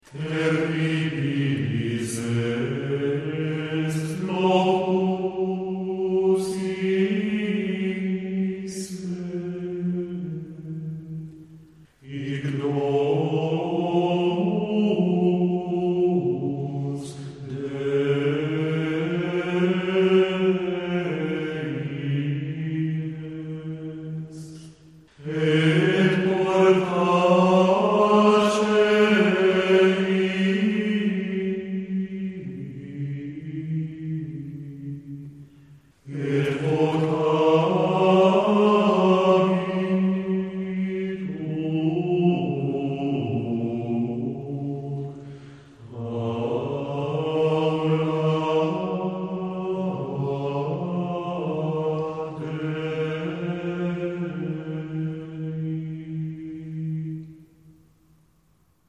Terribilis est locus iste, antifona dall'introito — Kantores 96 | Cd Amadeus-Darp, 1996